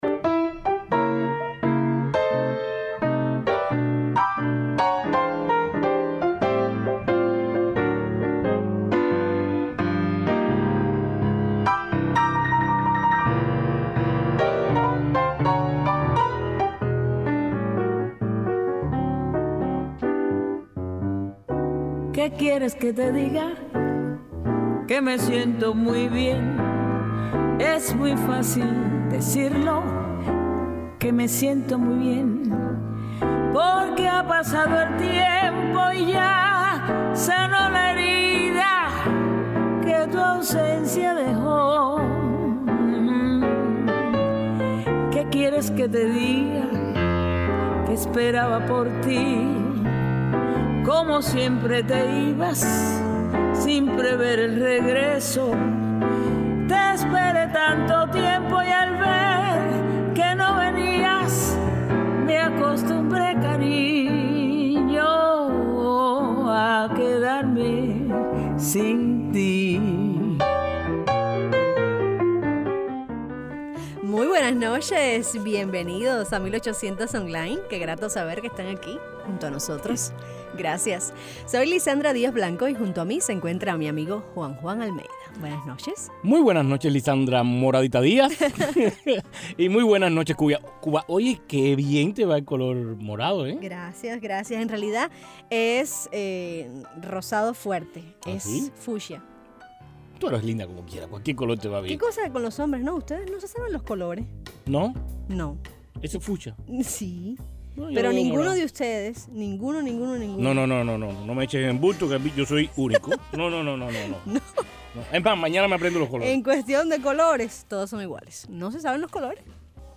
El músico cubano Boris Larramendi en el programa radial 1800 Online